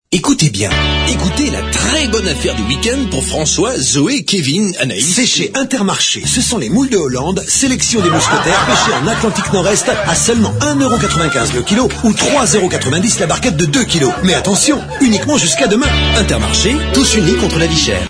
Publicité non trafiquée.mp3 (142.55 Ko)
cette pub était diffusée sur toutes les radios en Métropole.